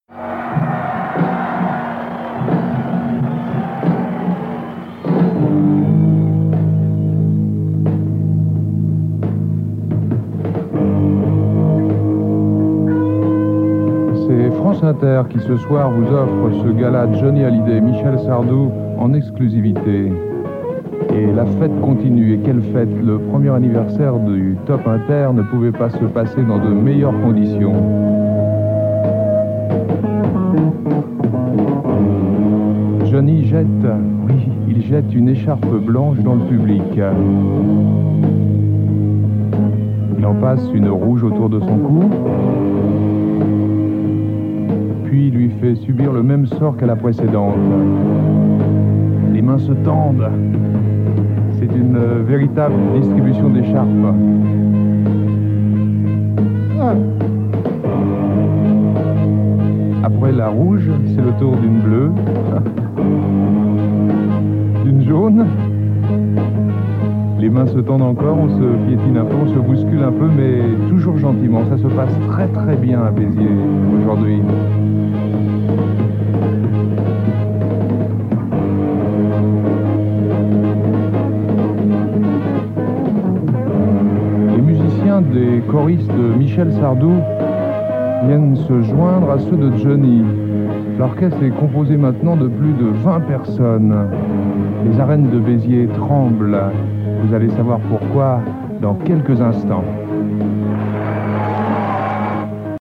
Arènes de Béziers